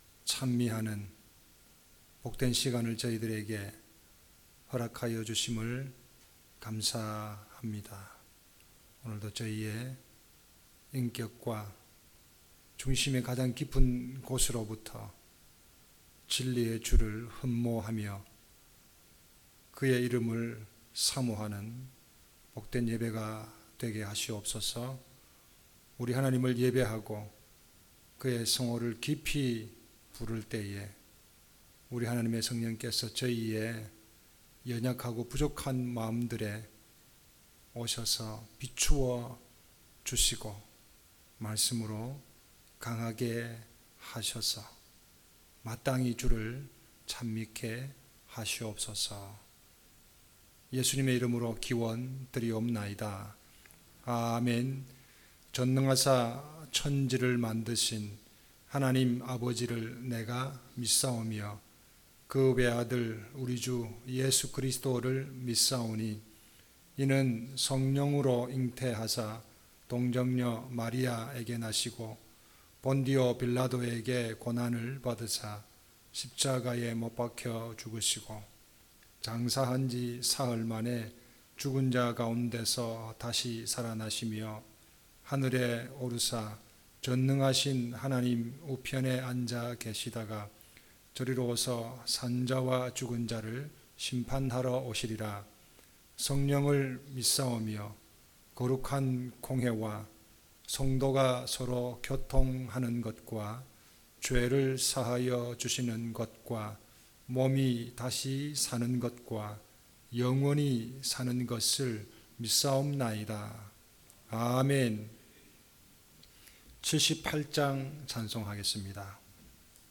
주일 설교